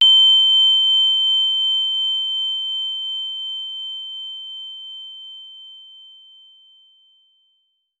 効果音チーン | Free-Sound-Workshop
効果音 ＞ チーン